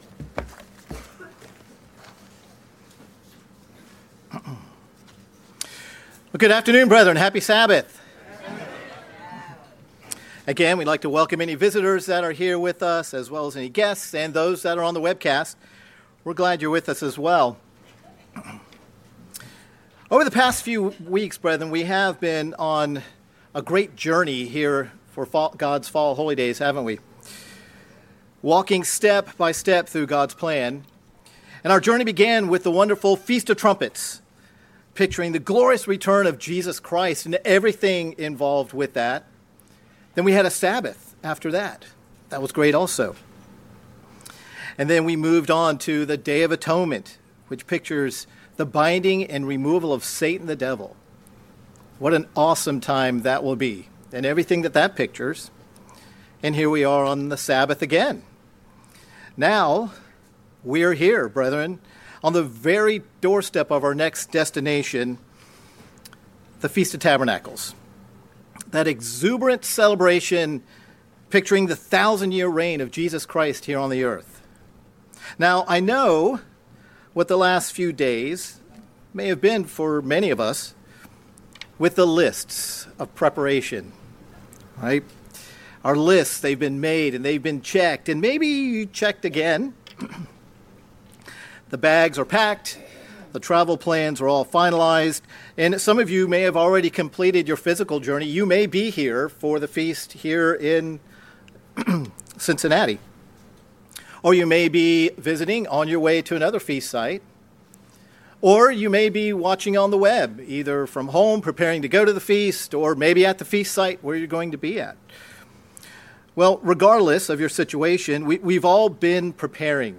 Sermons
Given in Cincinnati East, OH